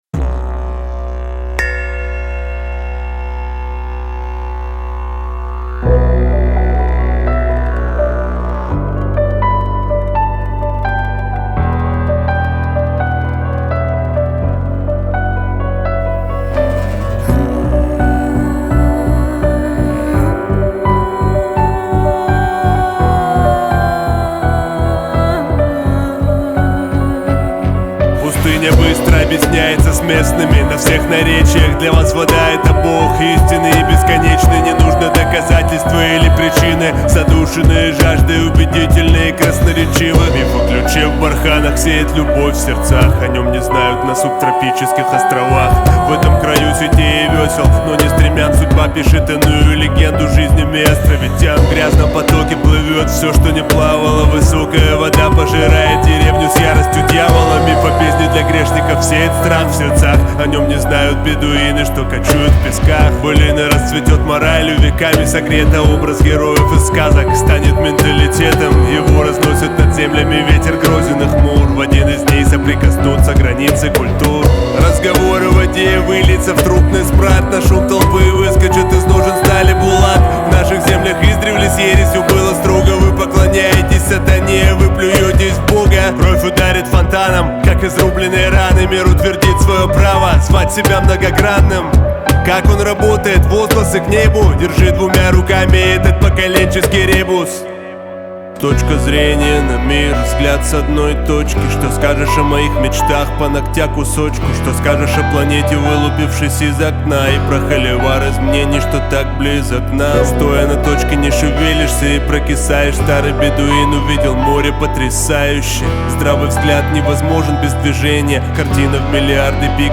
русских хип-хоп групп
В акустической версии этой песни ещё и музыка красивая.